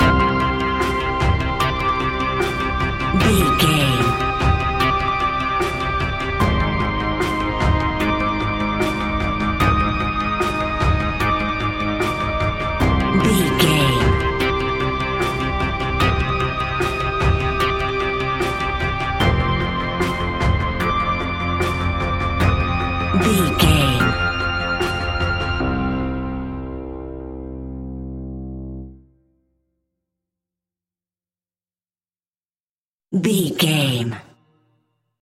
In-crescendo
Thriller
Aeolian/Minor
ominous
dark
eerie
synthesiser
drums
horror music
Horror Pads
Horror Synths